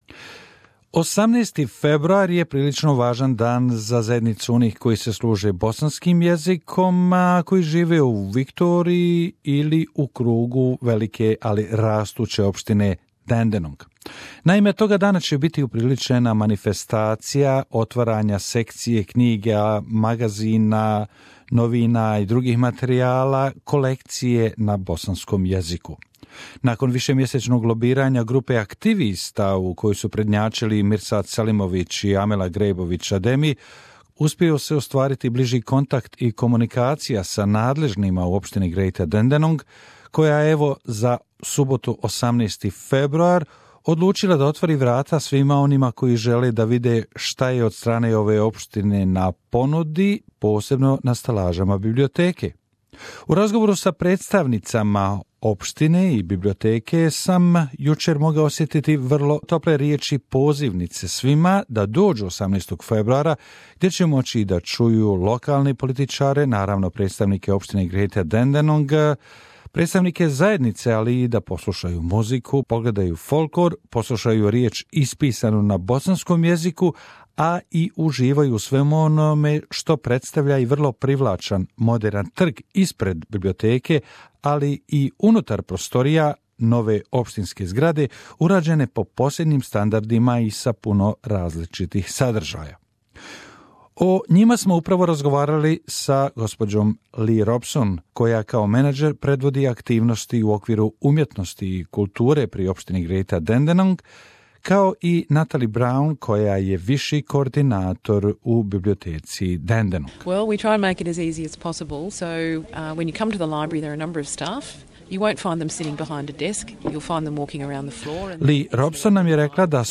Formal opening of the new Bosnian language section in the Dandenong Library will be held on Saturday 18th February at Harmony Square (225 Lonsdale St, Dandenong) Interview